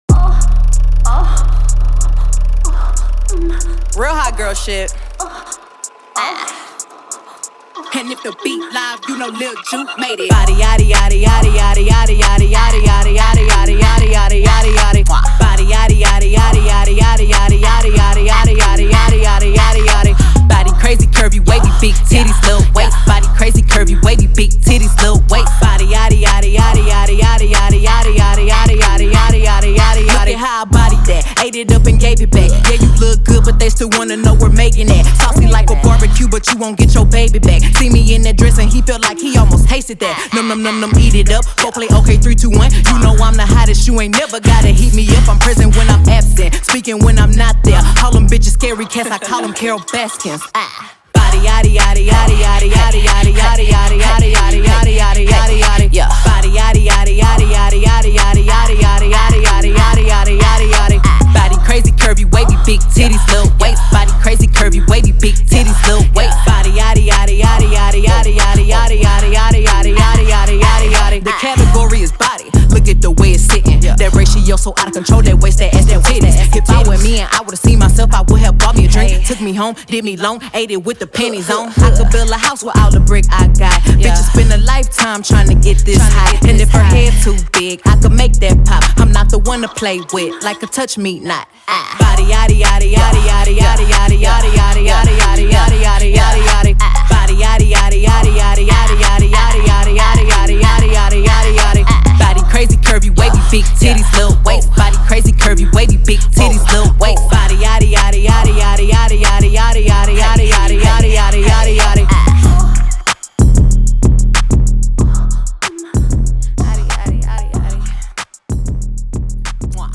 BPM94
MP3 QualityMusic Cut